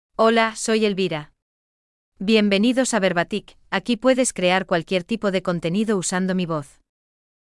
Elvira — Female Spanish (Spain) AI Voice | TTS, Voice Cloning & Video | Verbatik AI
Elvira is a female AI voice for Spanish (Spain).
Voice sample
Listen to Elvira's female Spanish voice.
Elvira delivers clear pronunciation with authentic Spain Spanish intonation, making your content sound professionally produced.